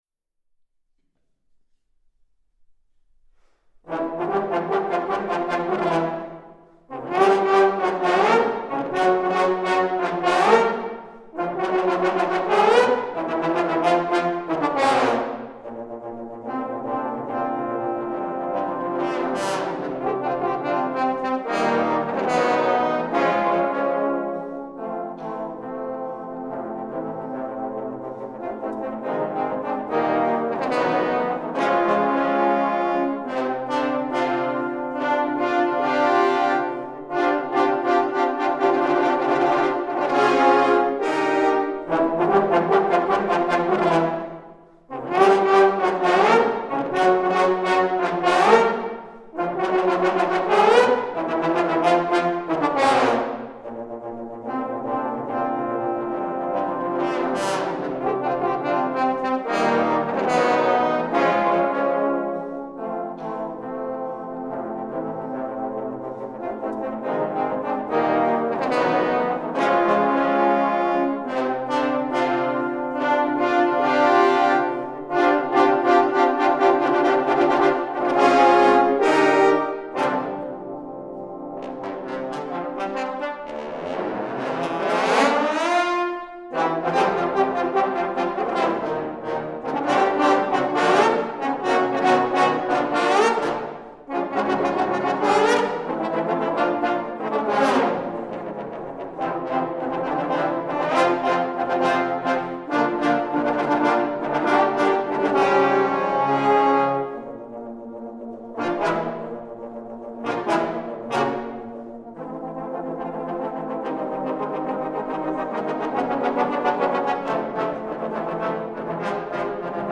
Voicing: Trombone Ensemble